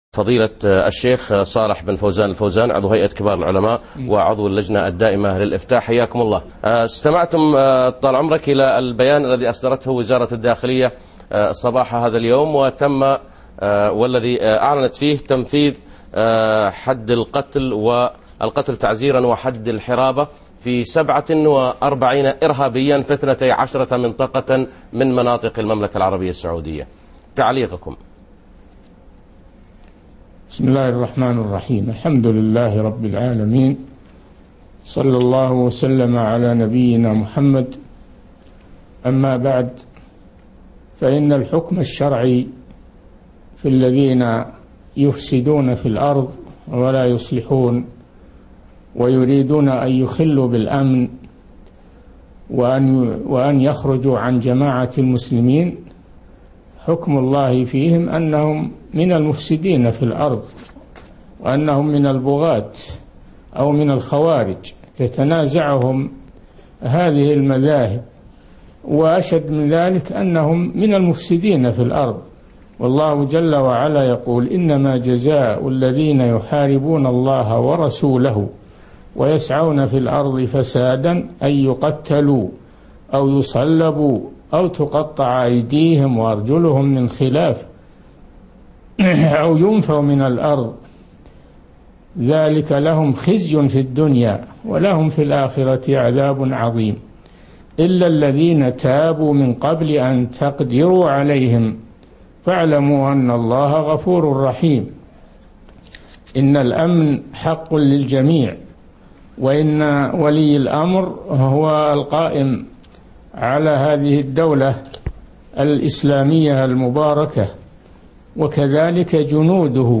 تعليق الشيخ صالح بن فوزان الفوزان عضو هيئة كبار العلماء على تنفيذ حكم القصاص بحق 47 إرهابياً